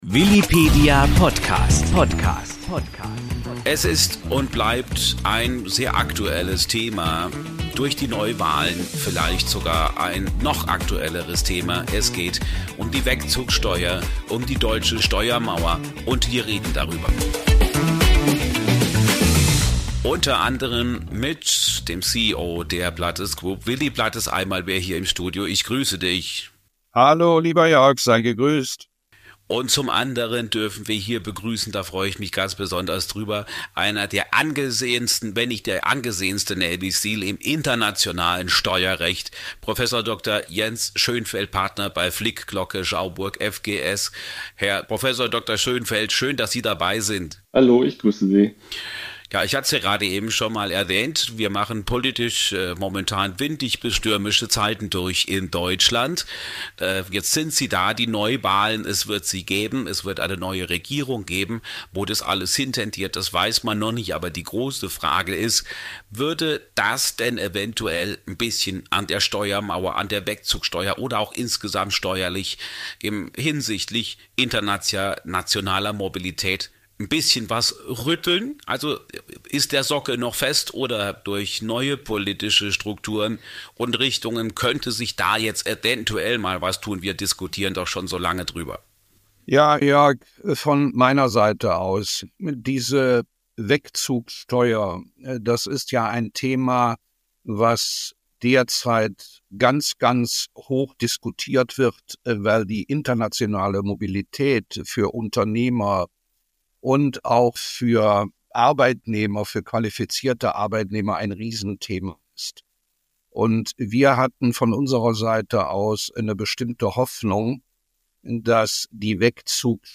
Eine Diskussion